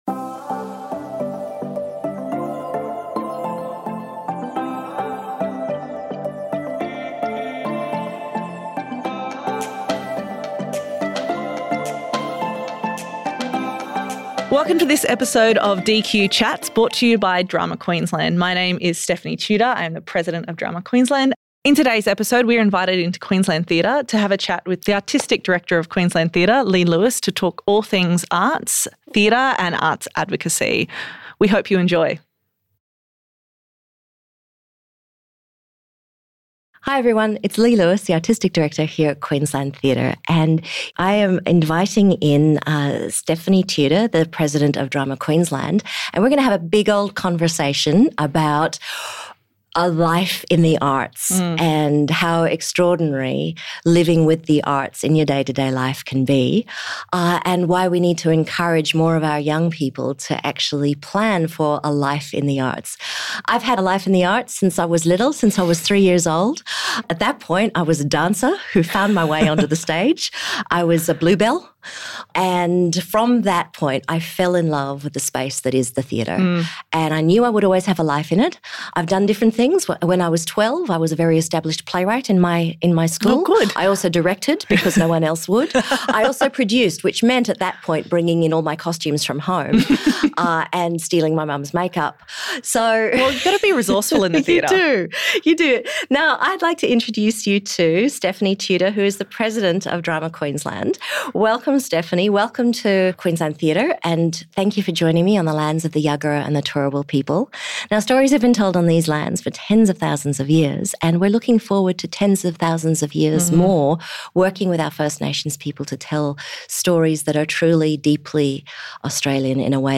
The conversation highlights the ways in which the theatre can be exciting, challenging and change the thinking of our young people so that they’re empowered to approach the world with empathy and passion.